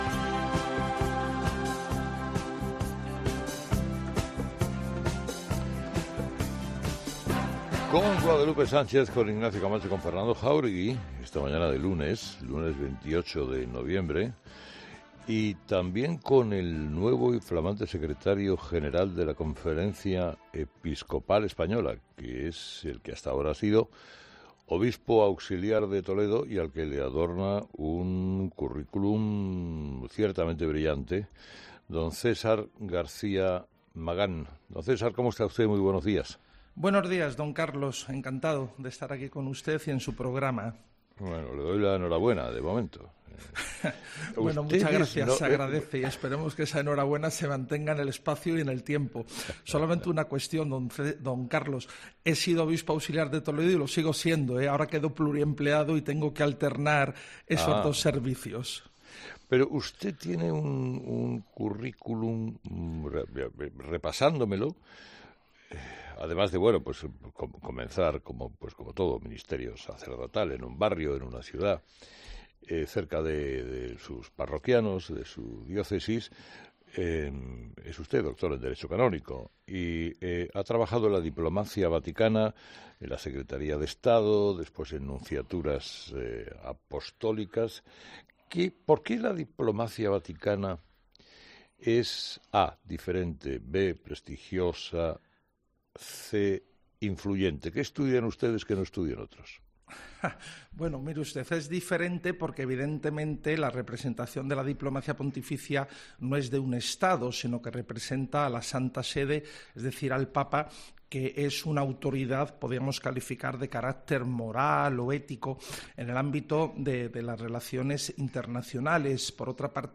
El nuevo secretario general de la CEE ha sido entrevistado este lunes por Carlos Herrera y hemos podido conocer también su lado más humano